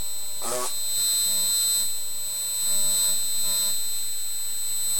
Hello with tonal echo
This below was recorded in the air by a parabolic microphone. Perhaps it shows the echo of the spoken word better when projected by an ultrasound speaker probably because of of the signals bouncing of walls or even maybe due to the way the signals travel via the powerline.
hello and echo
hello.mp3